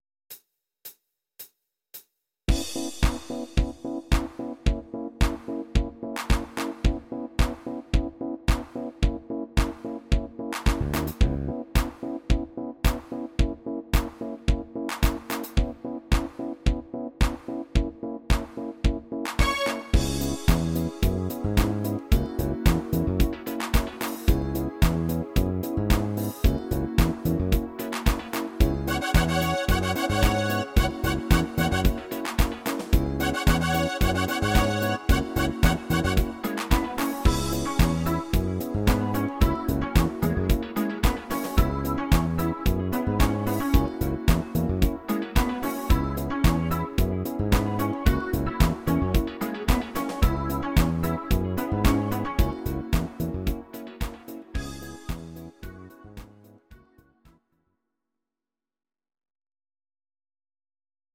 These are MP3 versions of our MIDI file catalogue.
Your-Mix: Disco (724)